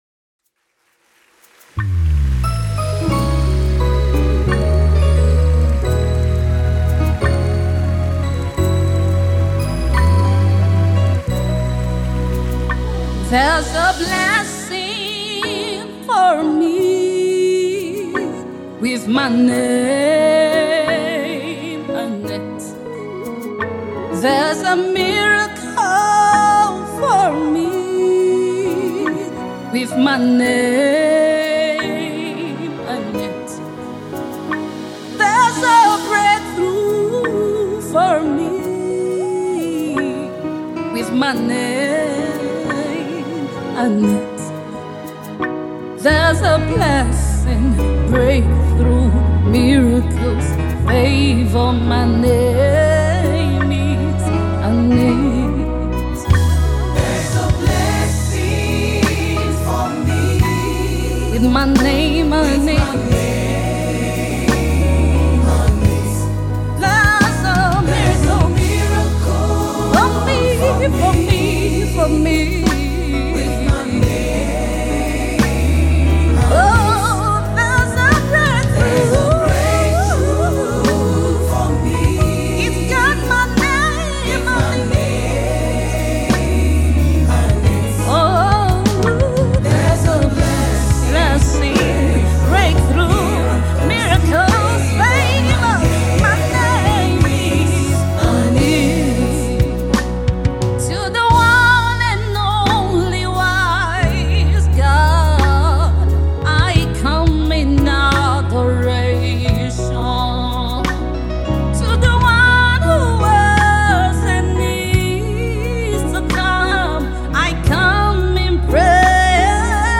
gospel music
a beautiful and soul-lifting song